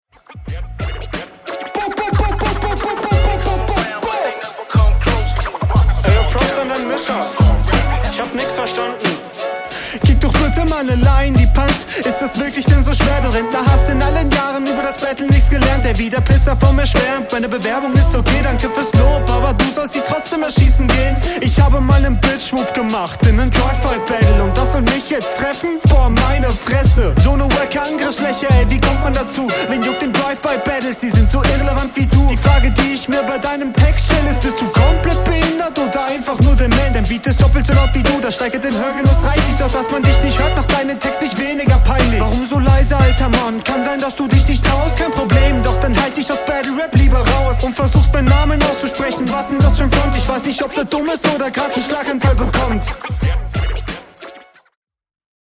Schöner Oldschool Vibe und Stimme passt auch sehr gut dazu. Soundtechnisch ist da noch Luft …